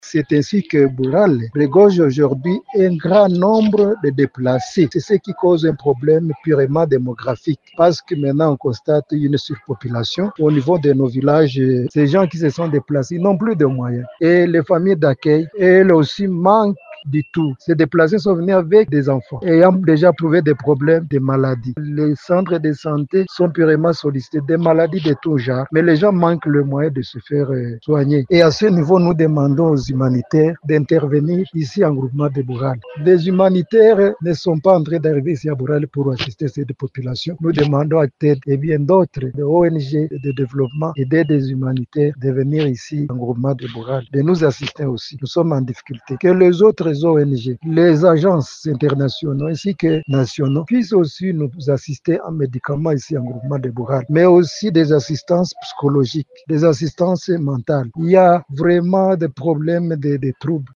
Dans un entretien avec Radio Maendeleo